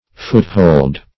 Foothold \Foot"hold`\, n.